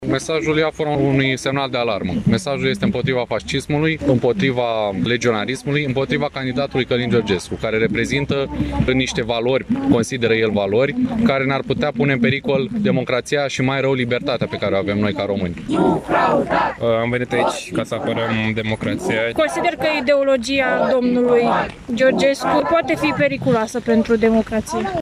Aproximativ 100 de persoane, majoritatea tineri, au protestat, vineri seară, în Piața Unirii din Iași, pentru a cincea zi consecutiv, față de Călin Georgescu, candidatul independent ajuns în turul al doilea al alegerilor prezidențiale.